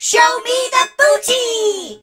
darryl_lead_vo_04.ogg